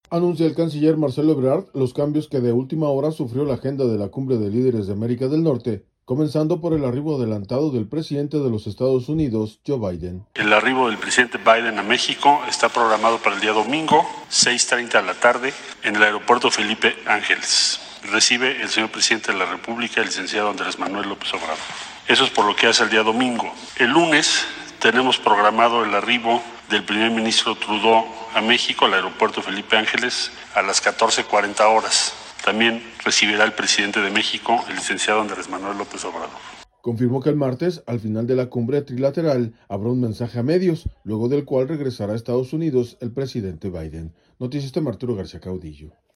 Anuncia el canciller Marcelo Ebrard, los cambios que de última hora sufrió la agenda de la Cumbre de Líderes de América del Norte, comenzando por el arribo adelantado del presidente de los Estados Unidos, Joe Biden.